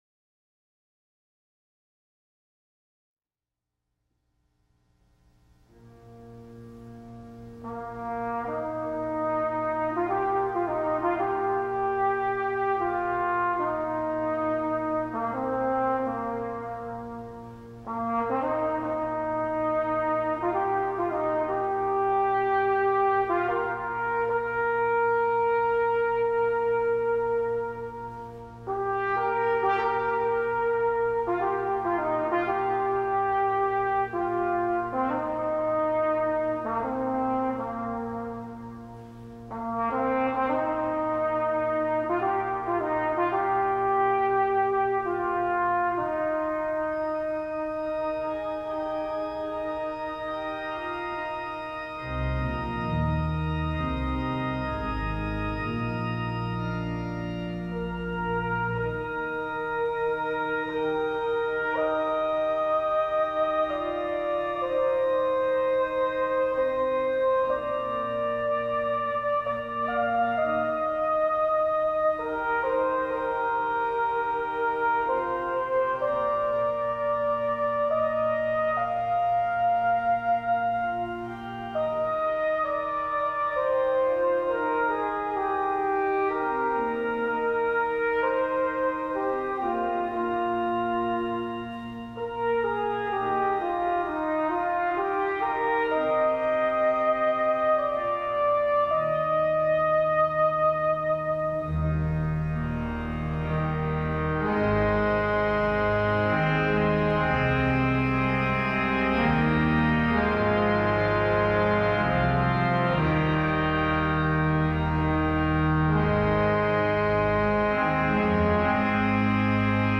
Voicing: Flugelhorn